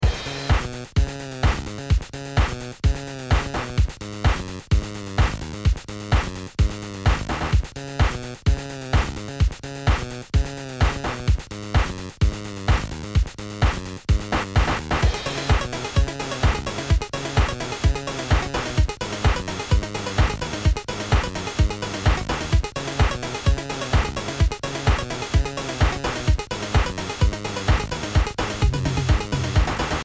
programmatically generated 8-bit musical loops